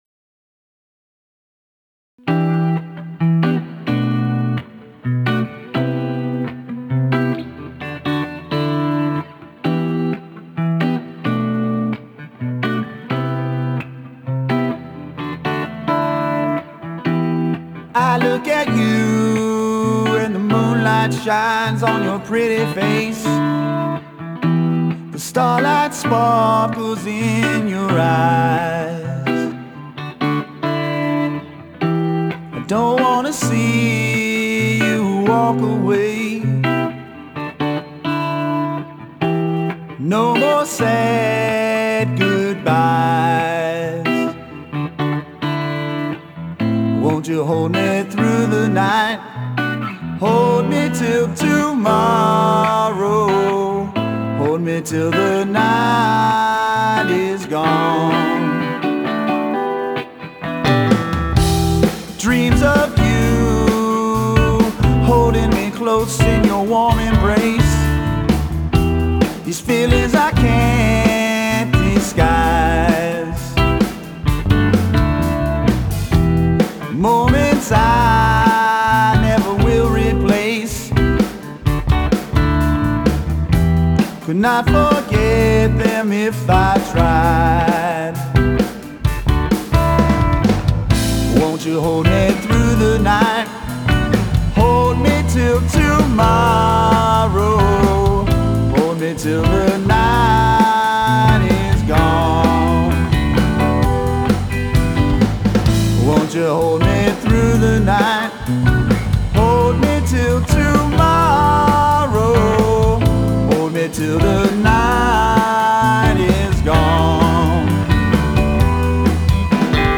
Genre: Rock, Funk Rock, Blues